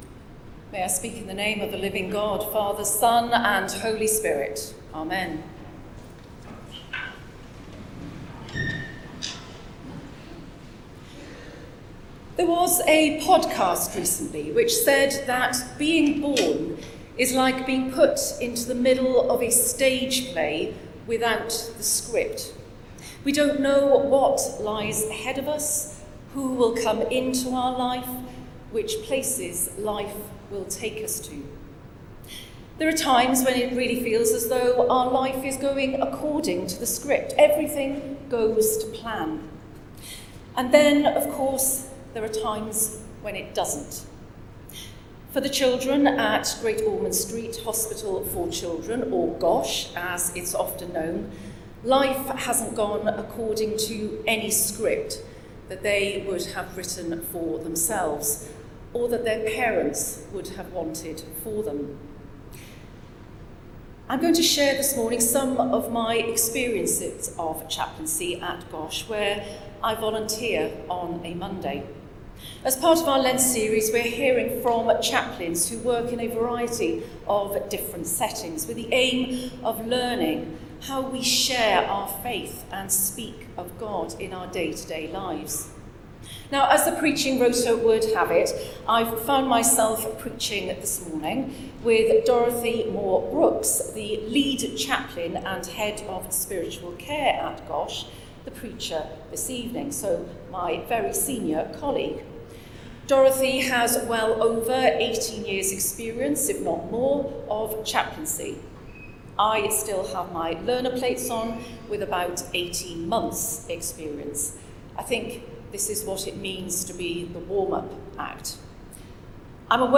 These have been created from the YouTube video but have been level-adjusted and noise-reduced to improve the sound quality
Lent Sermons Recordings 2025